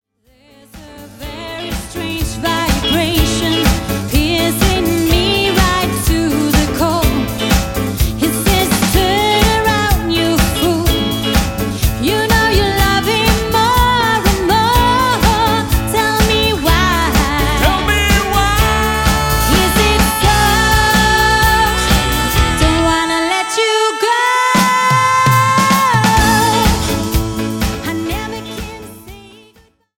live musik til din fest - pop & rock ørehængere fra 7 årtier
• Coverband